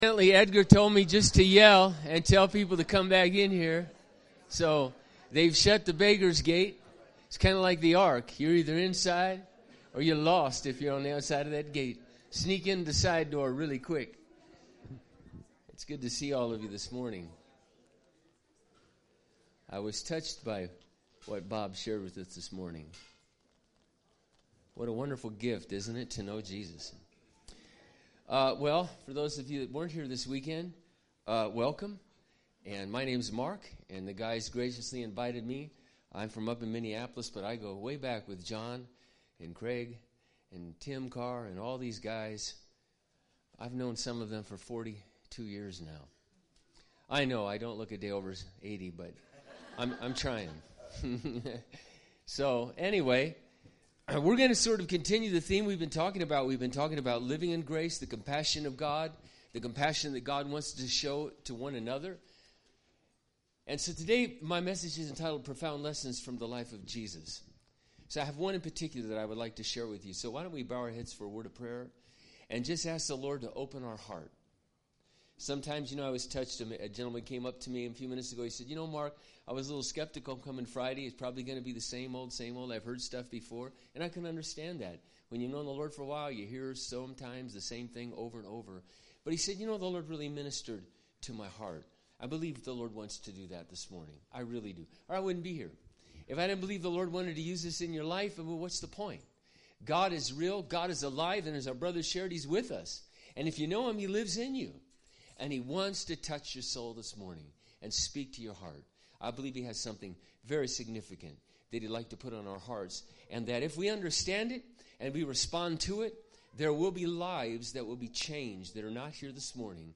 BG Archives Service Type: Sunday Speaker